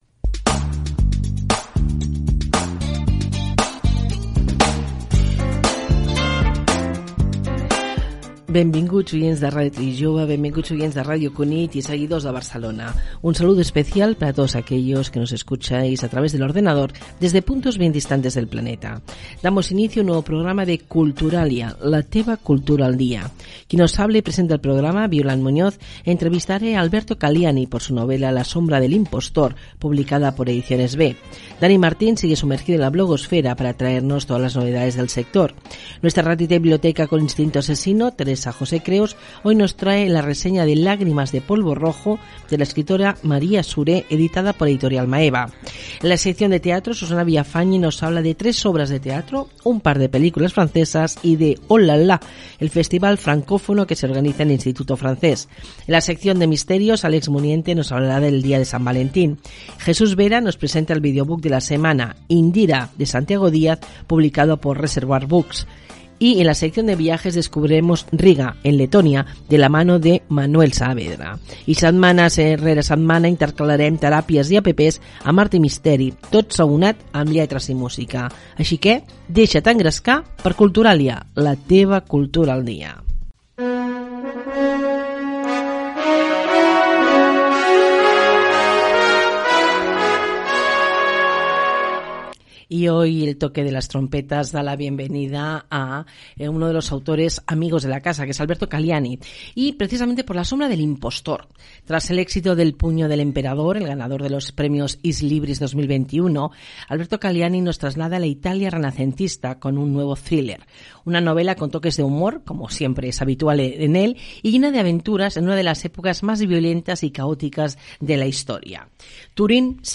Identificació de les dues ràdios (Ràdio Trinijove i Ràdio Cunit) que emeten el programa, sumari de continguts